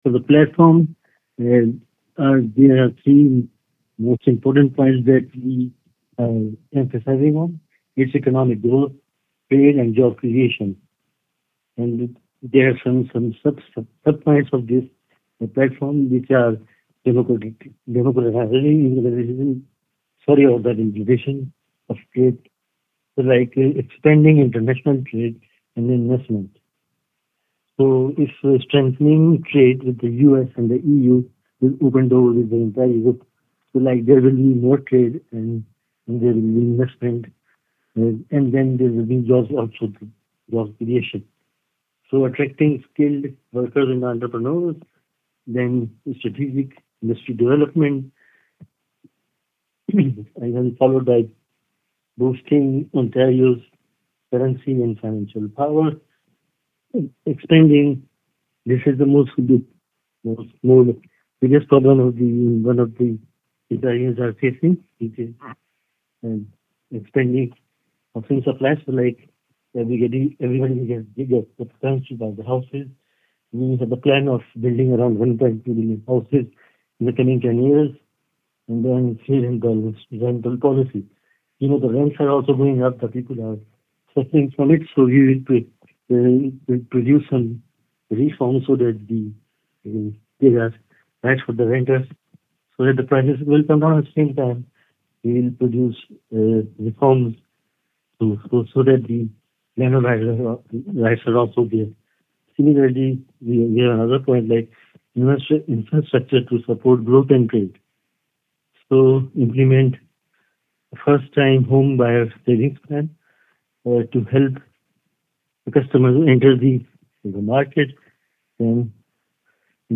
He was available by phone for this interview.
Here’s our interview: